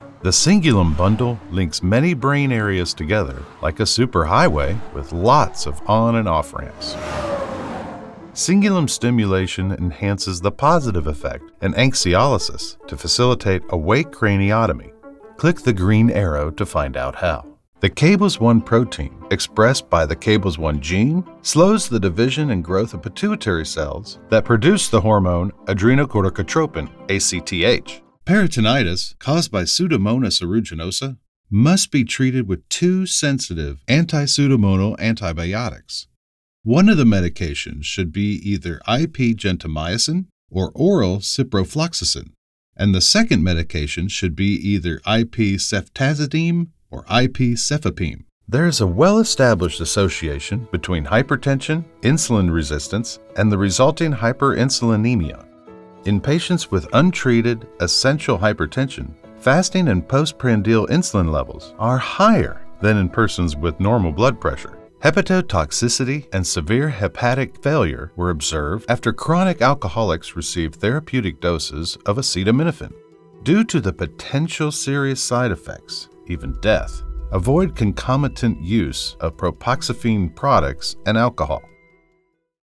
Medical